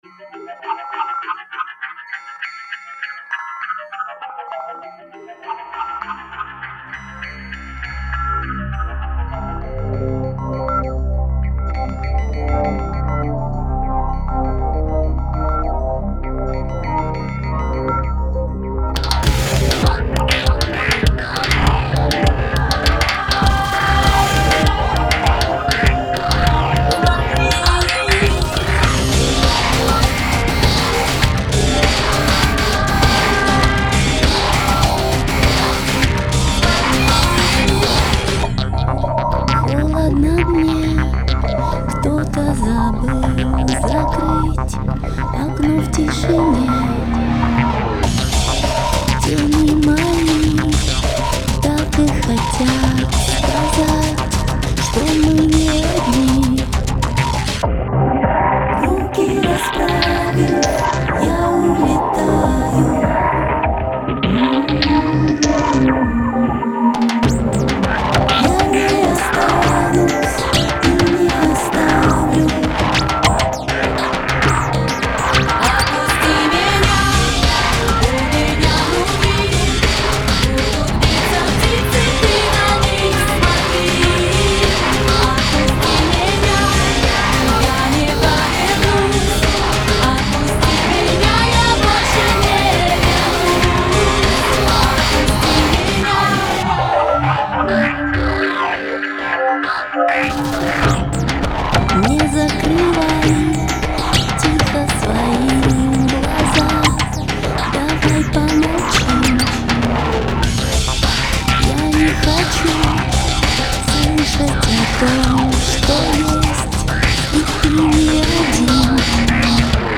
Жанр: local-indie